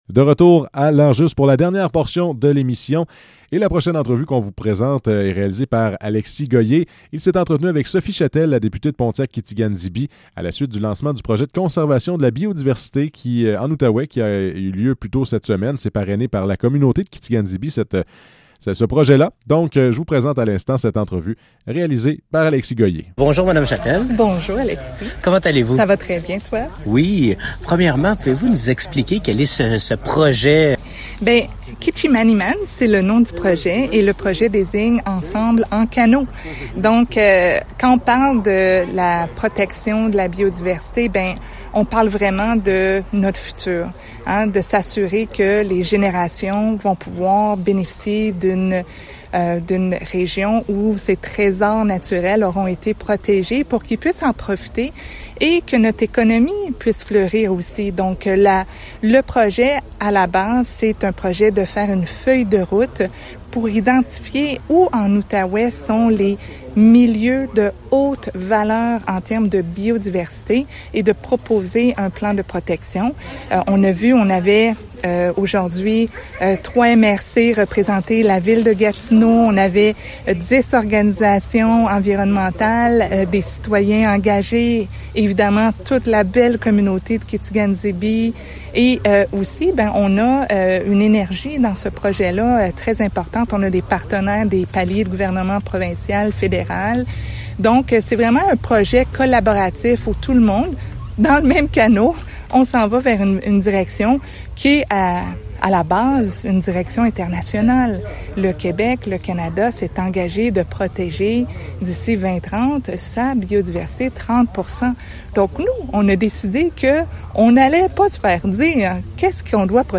Entrevue avec Sophie Chatel